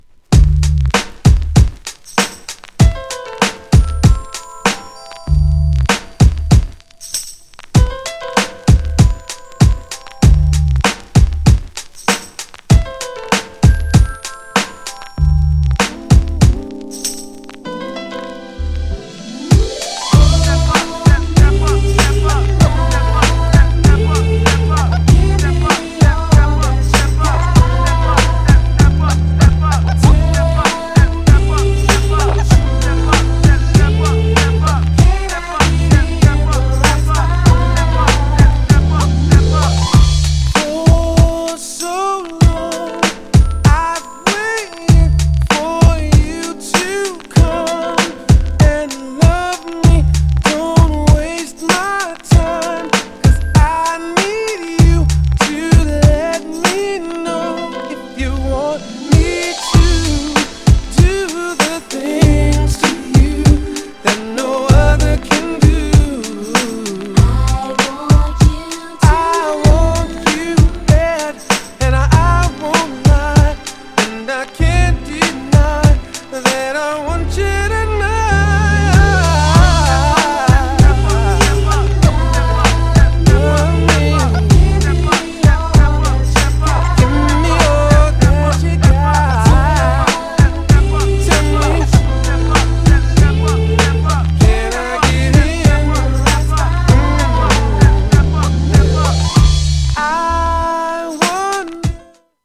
LP VERSIONに加え、US未収録のJAZZYな生演奏によるLIVE VERSION収録のレアなイタリア盤!!
GENRE R&B
BPM 96〜100BPM